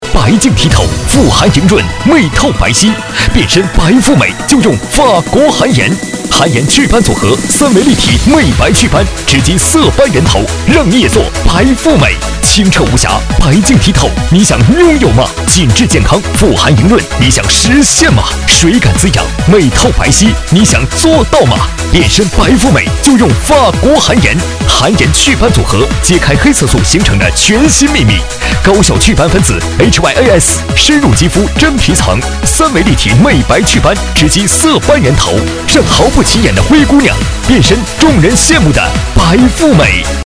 男国202_广告_电购_含颜.mp3